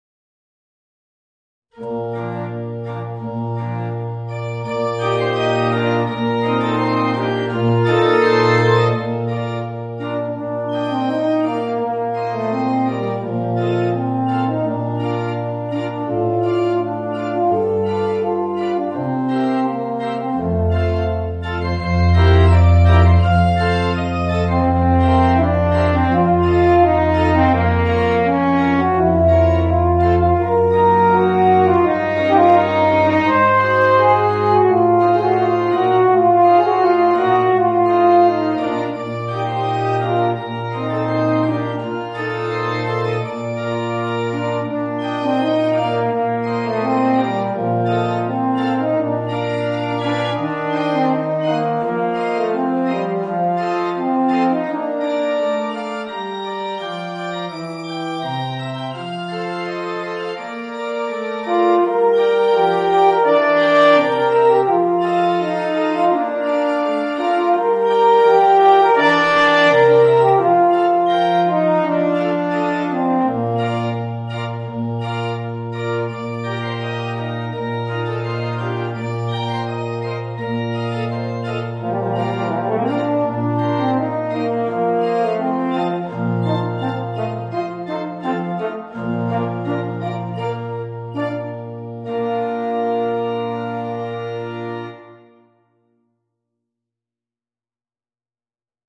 Voicing: Eb Horn and Organ